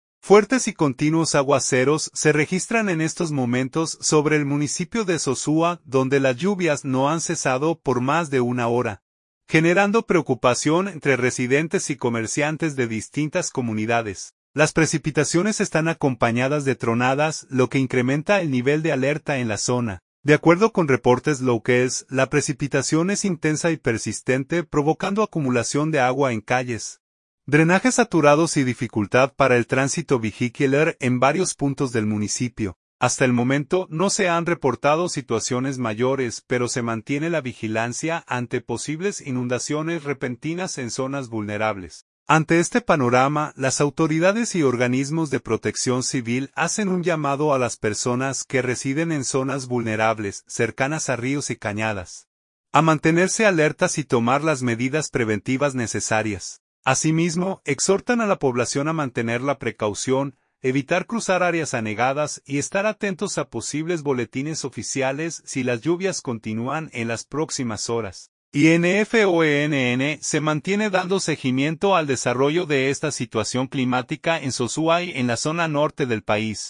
Tronadas y fuertes lluvias mantienen en alerta a Sosúa
Las precipitaciones están acompañadas de tronadas, lo que incrementa el nivel de alerta en la zona.